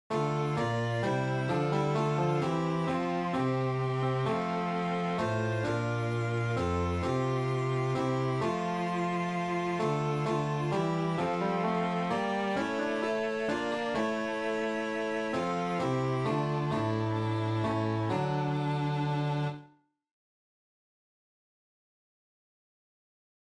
Chorproben MIDI-Files 508 midi files